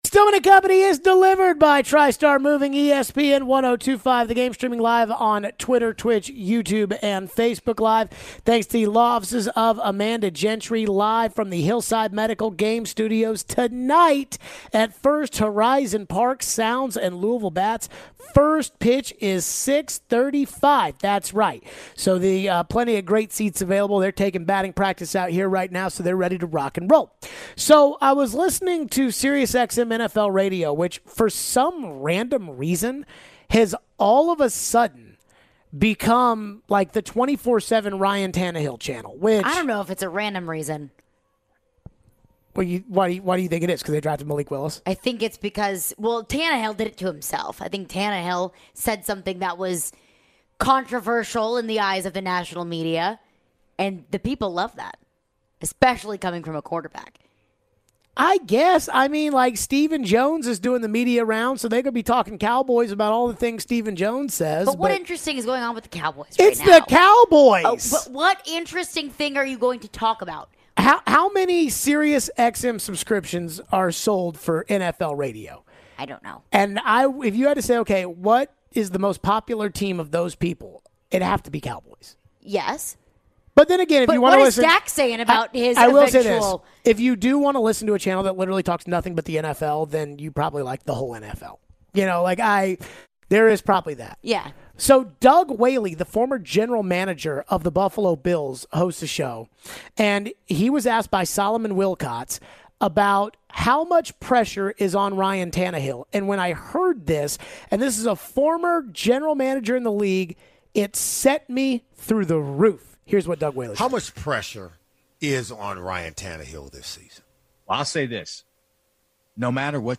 We take a look at 2021 Tannehill vs 2019/2020 Tannehill: why was Tannehill so much worse this past season than the previous two seasons? Trevor Matich joins the show to talk about Treylon Burks leaving rookie minicamp and gives his thoughts on the Titan's upcoming season.